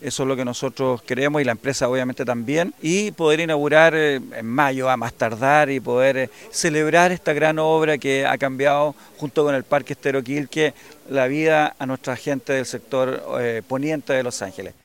El alcalde de Los Ángeles, Esteban Krause, entregó la estimación al dar cuenta de la ejecución de las faenas que finalizan el próximo mes.